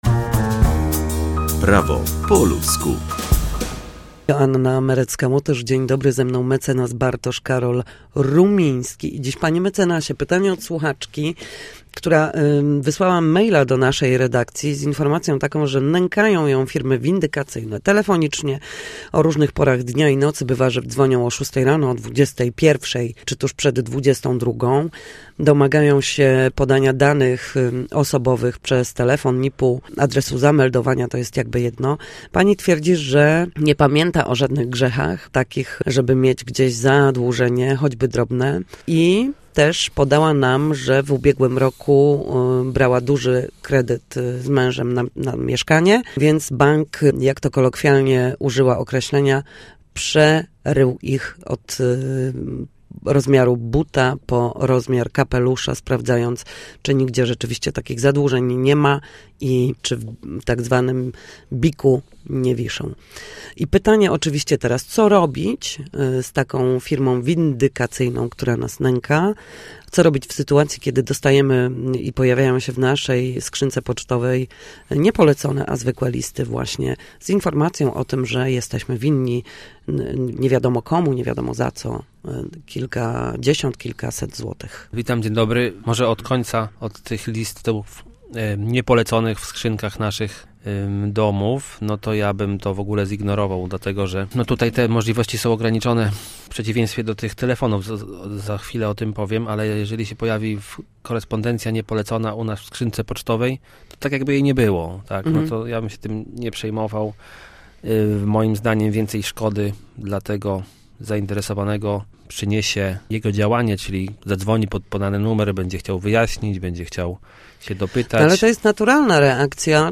W każdy piątek o godzinach 7:20 i 13:40 na antenie Studia Słupsk przybliżamy meandry prawa. Nasi goście – prawnicy – odpowiadają zawsze na jedno pytanie dotyczące zachowania w sądzie czy podstawowych zagadnień prawniczych.